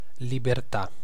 Ääntäminen
IPA : /ˈlɪbɚti/